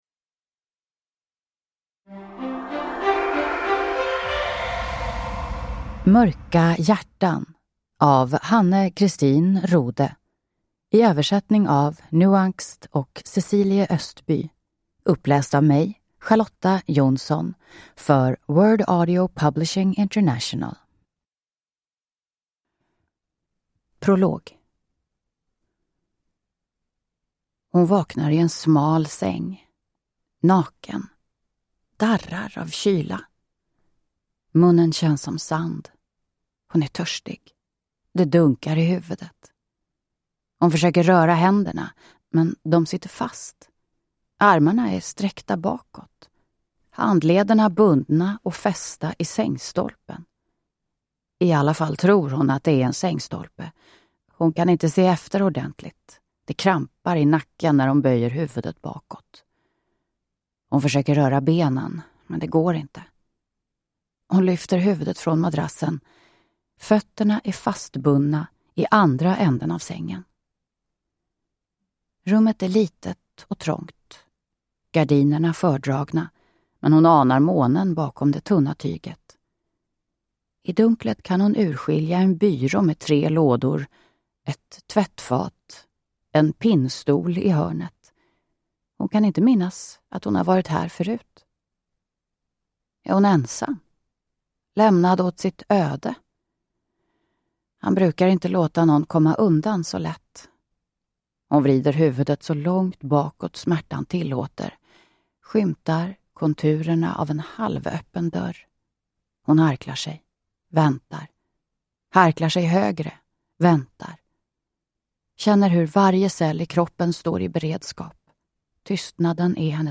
Mörka hjärtan – Ljudbok